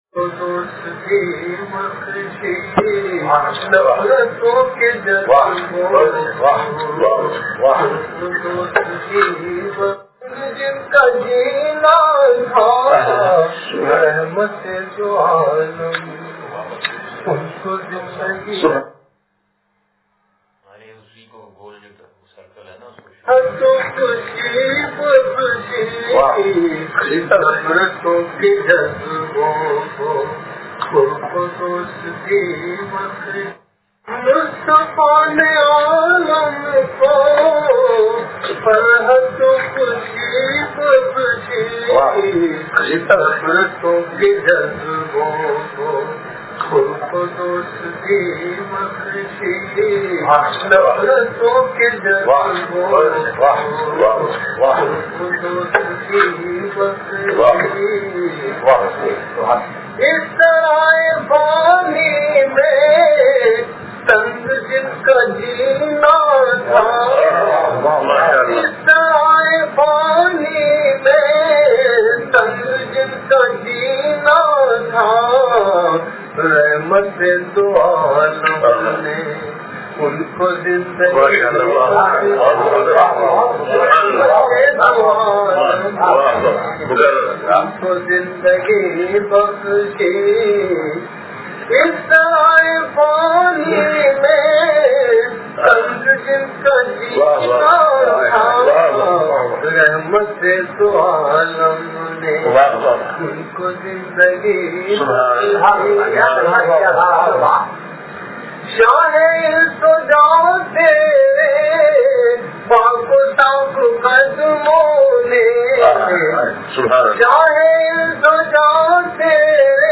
نعت شریف – یہ صبح مدینہ یہ شامِ مدینہ – مجلس بروز بدھ